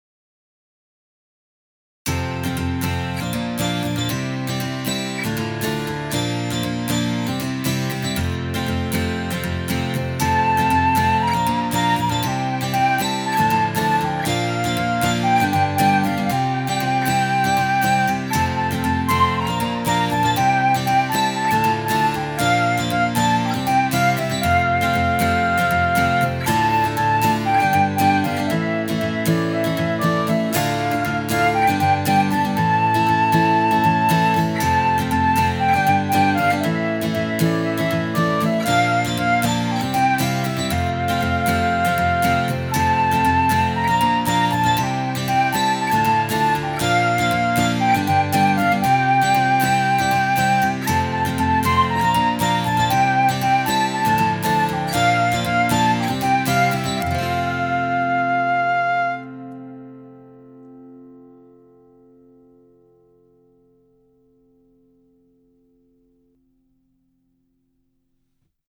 InstrumentalCOUPLET/REFRAIN